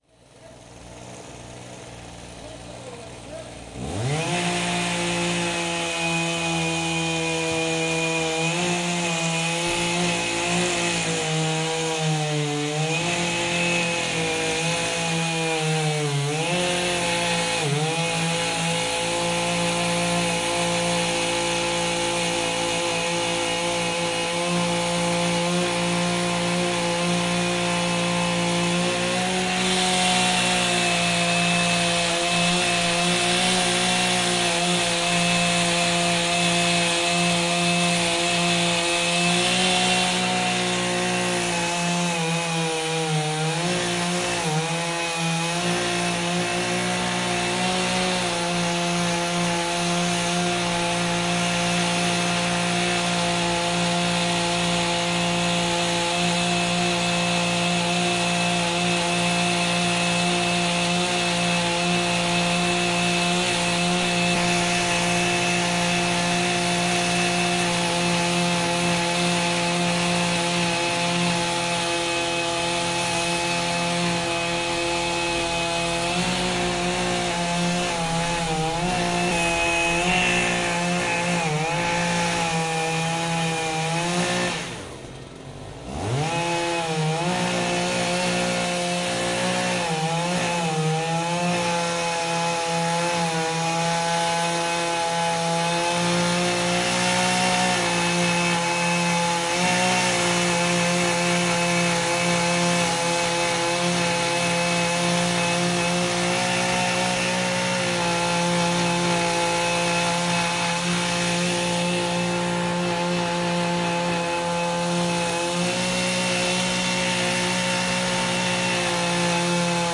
链锯
描述：切开通过一个大树桩的锯。
标签： 树木 电锯
声道立体声